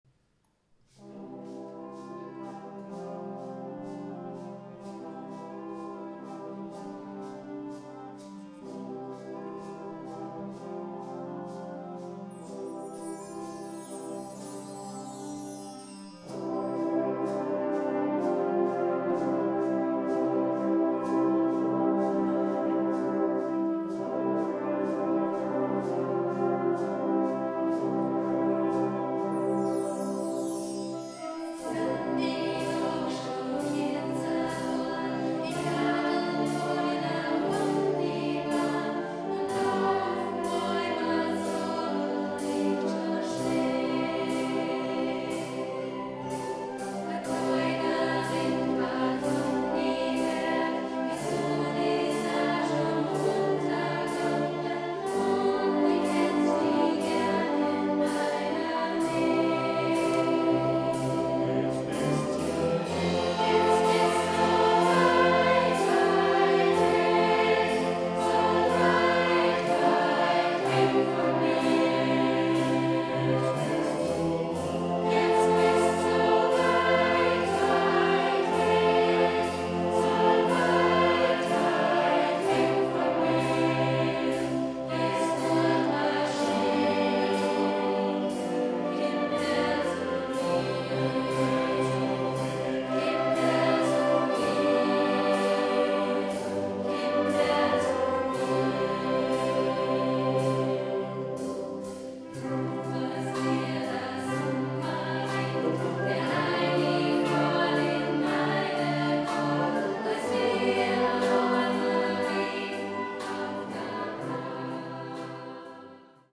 Gattung: Moderne Blasmusik
Besetzung: Blasorchester
Der Austro-Pophit!